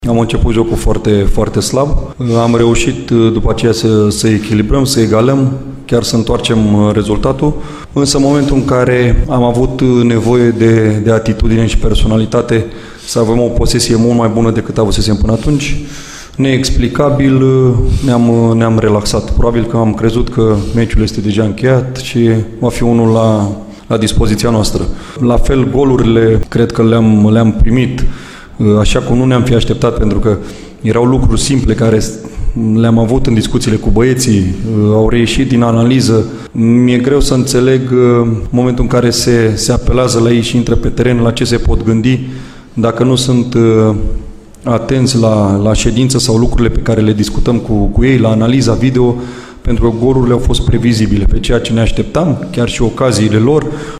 Altfel, selecționerul României consideră că toate cele trei goluri încasate la Erevan au venit din faze previzibile: